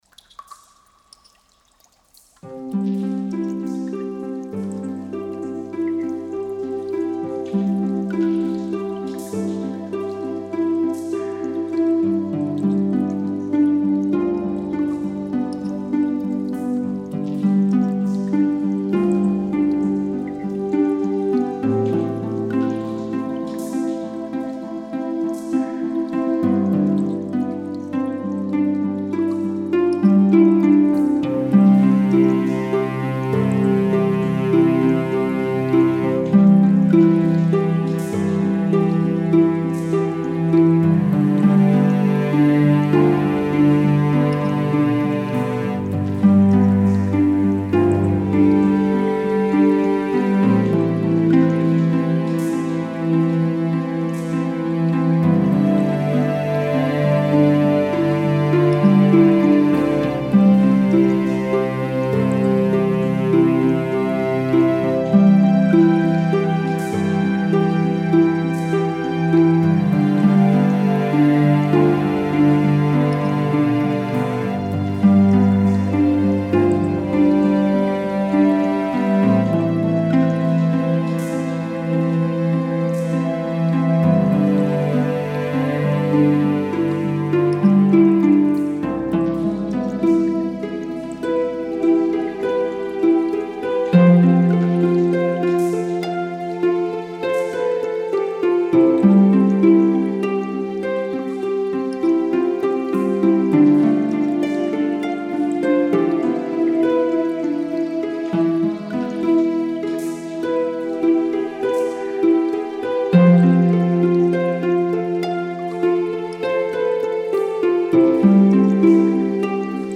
אני שמח להגיש לכם את השיר החמישי בסדרה האינסטרומנטלית שלי